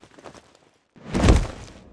shared_fall.wav